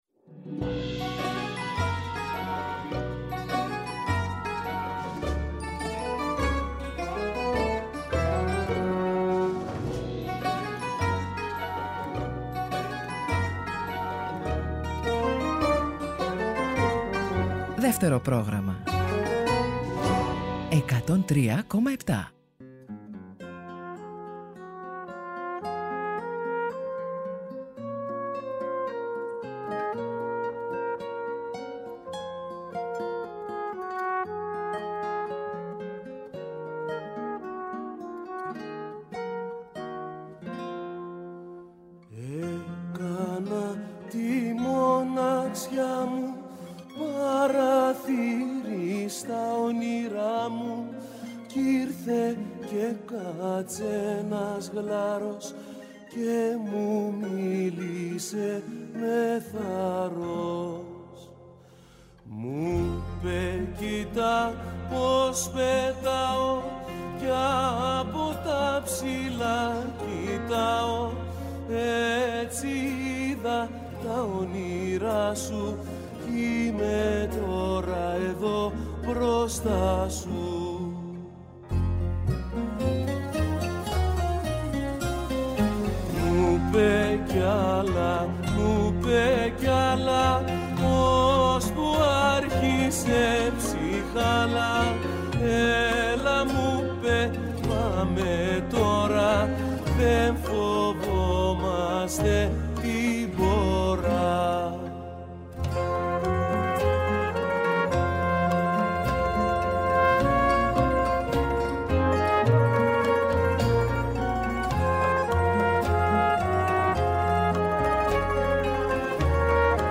μέσα από παλαιότερες και πρόσφατες συνεντεύξεις του.